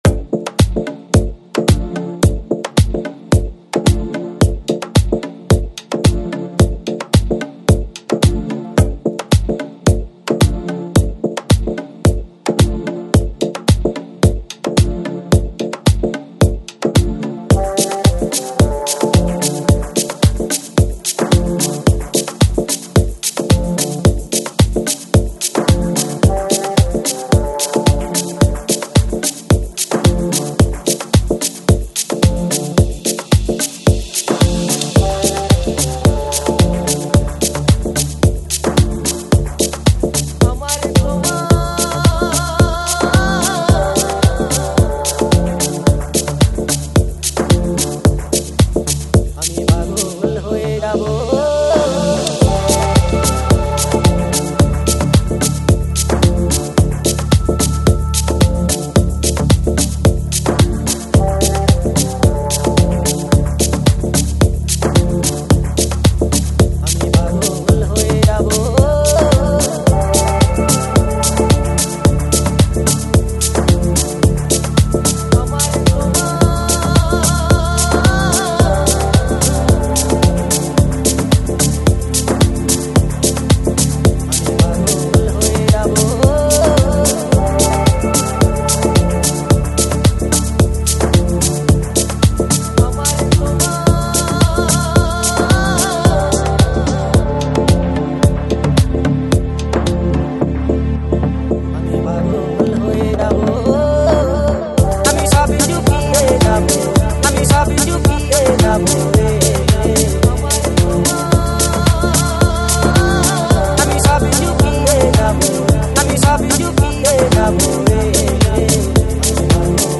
Жанр: House, Deep House, Organic House